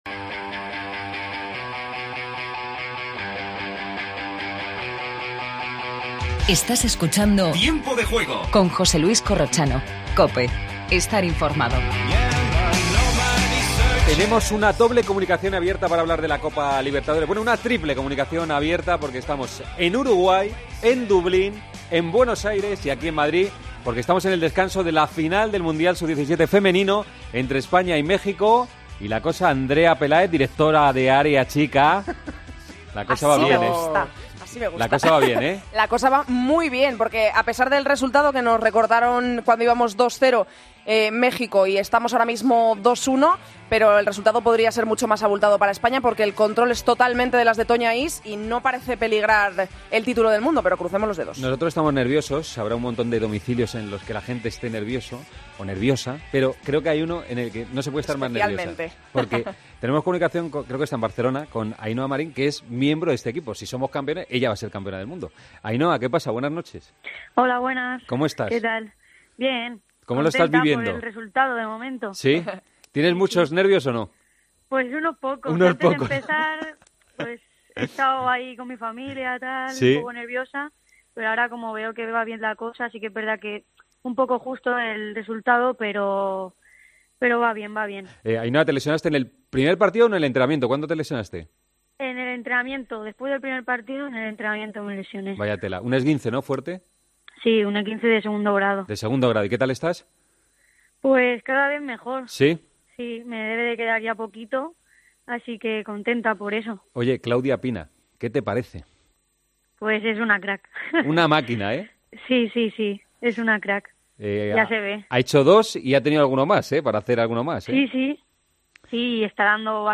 AUDIO: En directo, la final del Mundial sub17 femenino.